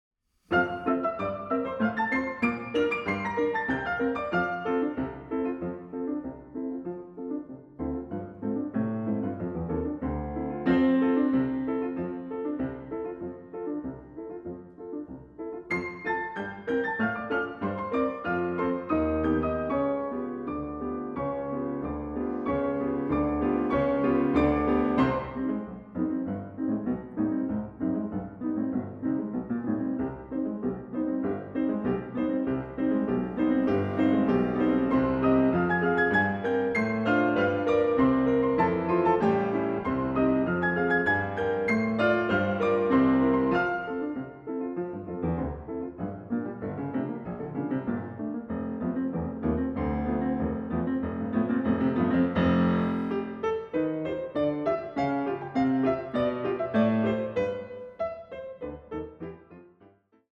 A piano journey through various European musical worlds
Piano